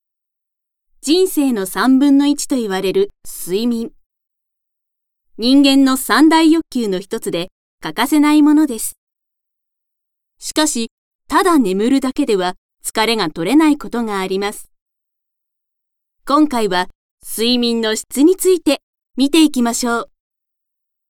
ボイスサンプル
ナレーション